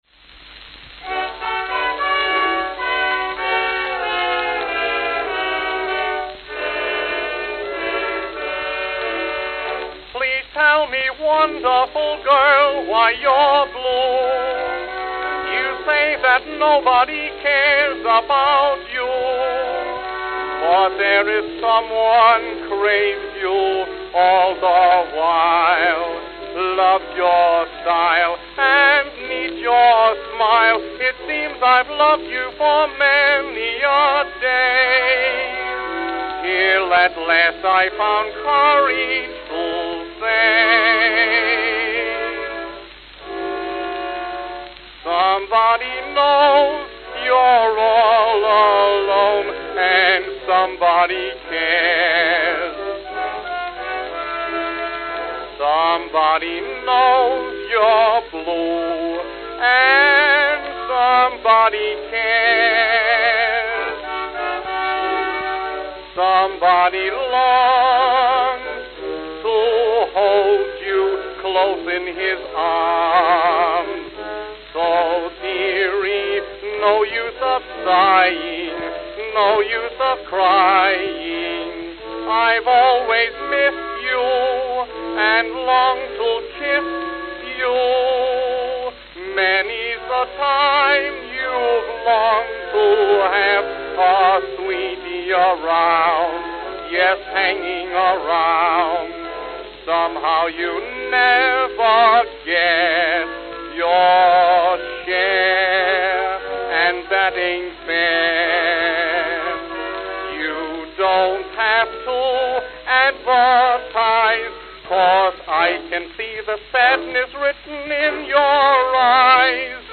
Note: Pressing ridge 0:00-0:28, filtered.